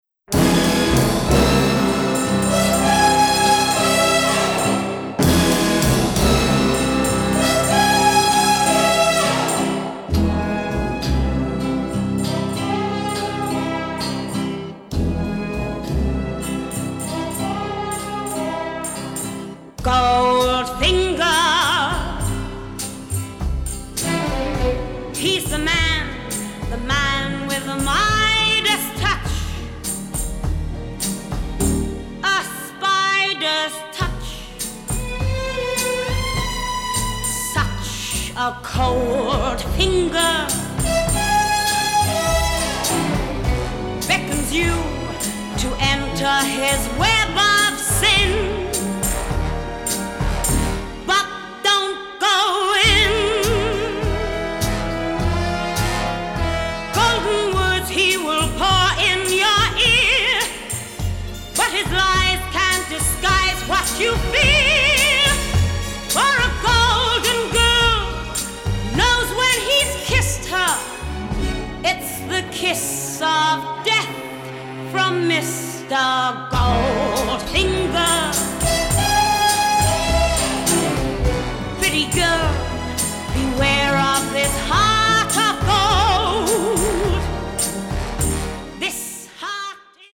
orchestral score